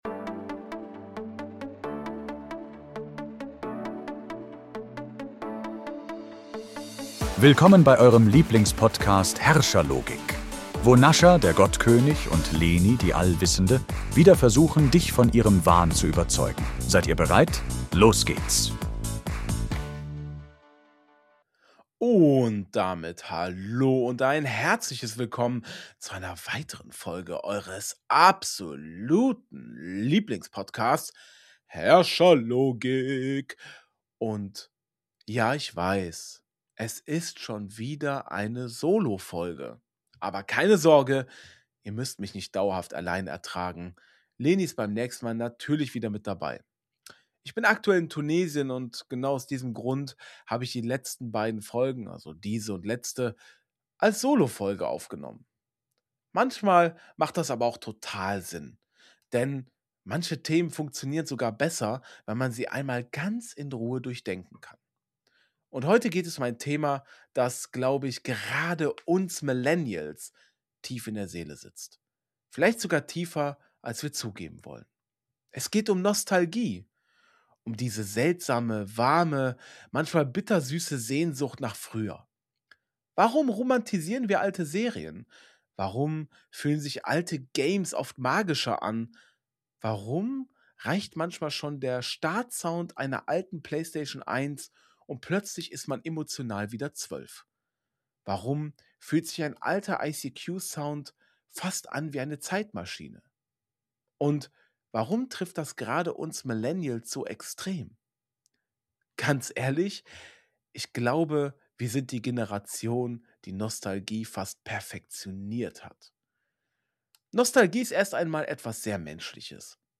einer ausführlichen Solo-Folge über ein Gefühl, das gerade viele